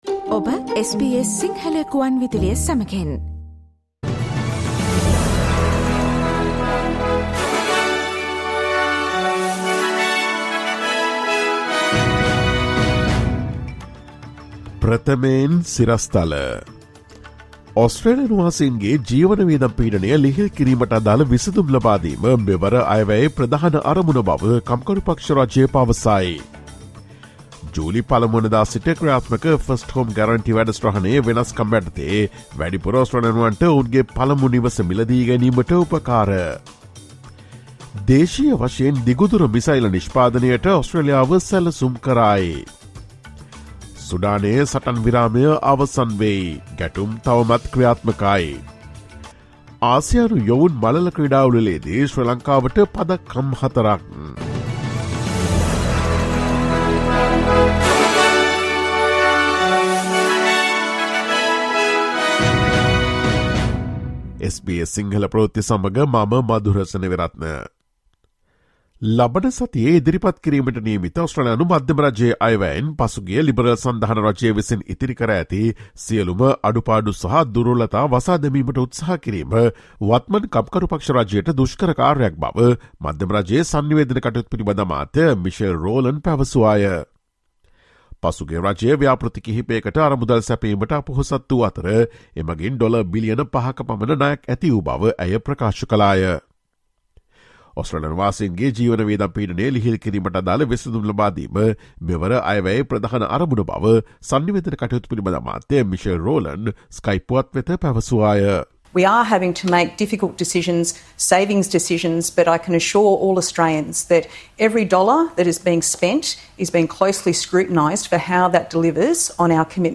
Listen to the latest news from Australia, across the globe, and the latest news from the sports world on SBS Sinhala radio news – Monday, 01 May 2023.